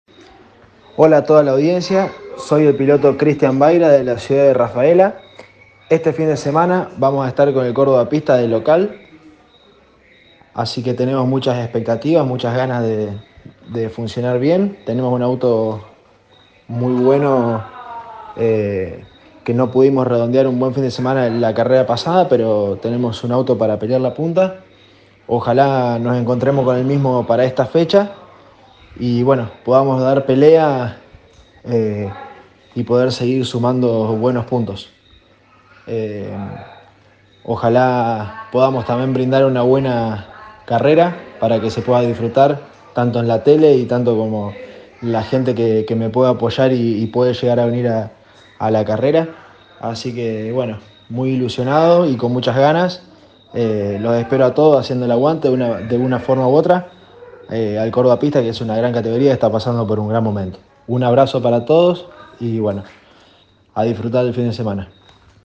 Los pilotos santafesinos realizaron declaraciones antes de la quinta fecha.